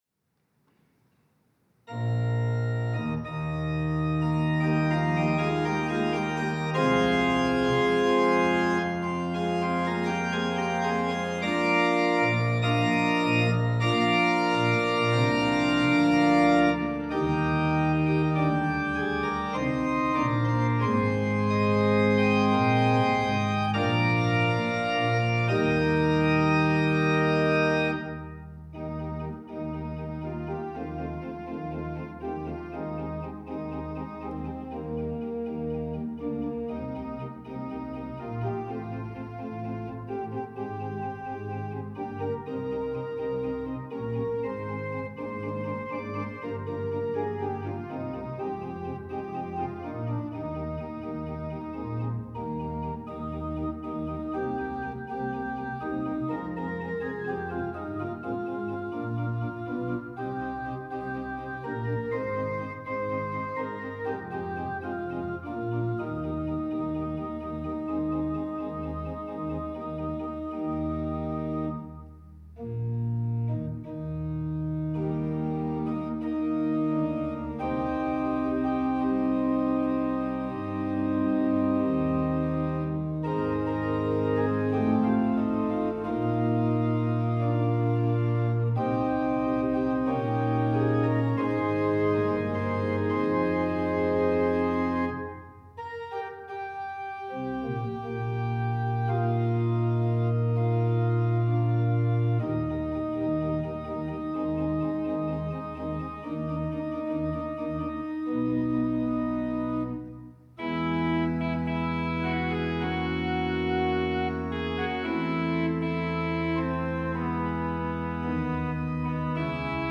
특송과 특주 - 나의 등 뒤에서